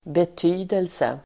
Uttal: [bet'y:delse]